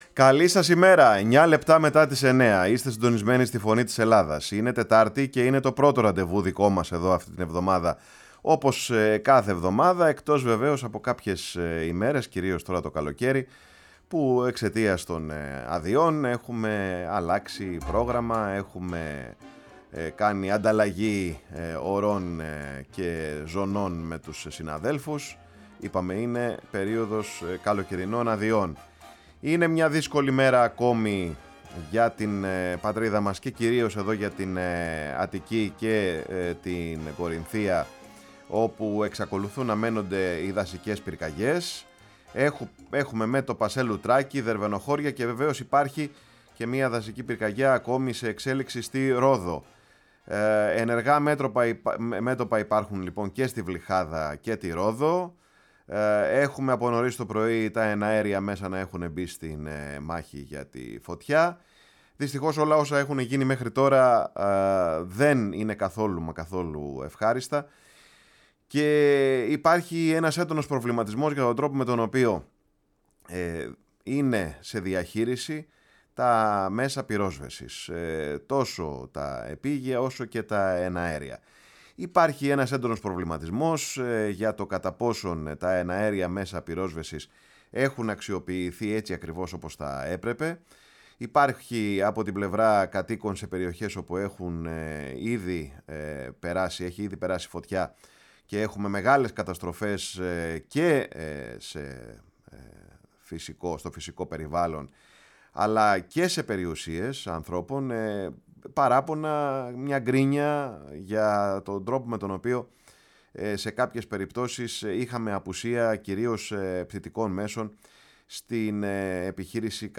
Στην εκπομπή της Τετάρτης, 19 Ιουλίου, φιλοξενήσαμε τηλεφωνικά τον καθηγητή γεωλογίας στο Πανεπιστήμιο της Αθήνας και πρόεδρο του ΟΑΣΠ, Ευθύμη Λέκκα, με τον οποίο συζητήσαμε τις συνέπειες των πυρκαγιών στο περιβάλλον, ειδικά σε μια περιοχή όπως η Αττική, με τις μεγάλες οικιστικές πιέσεις.
Συνεντεύξεις